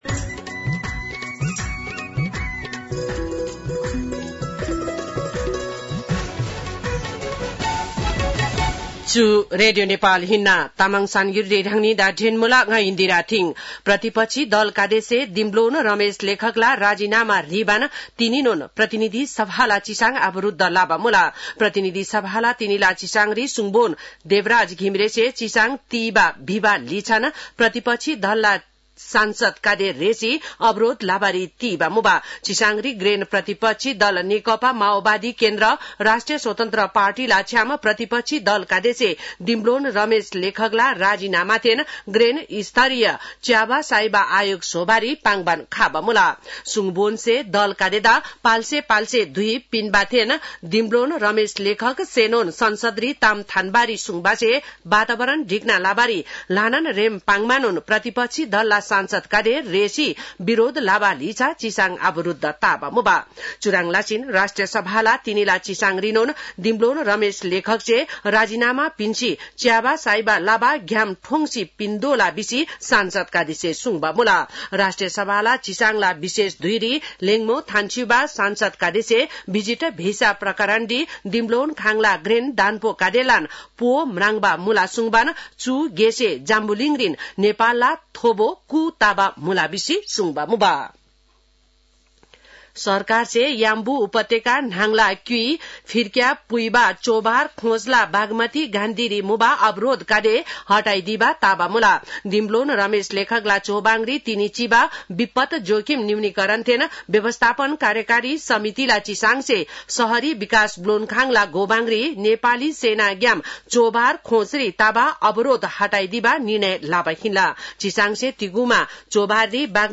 तामाङ भाषाको समाचार : १४ जेठ , २०८२